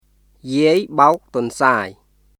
[ジアイ・バオク・トンサーイ　yiˑəi baok tʷɔ̀ｎsaːi]